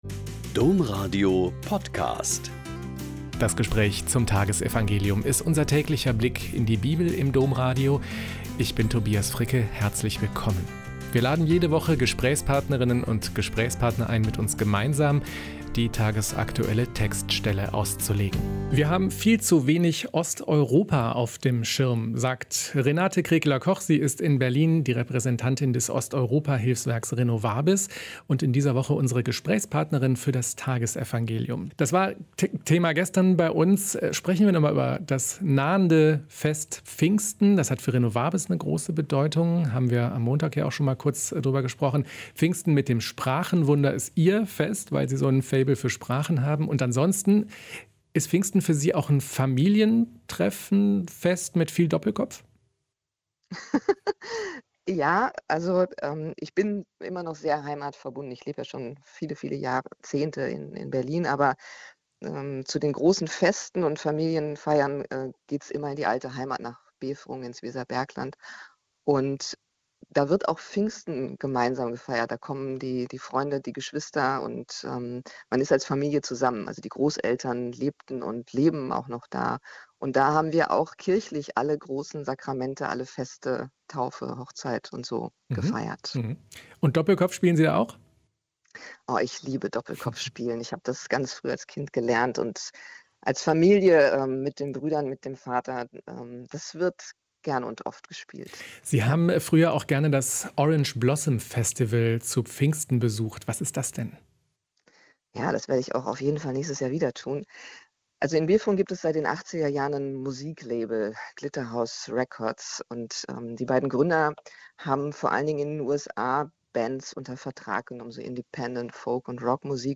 Joh 21,1.15-19 - Gespräch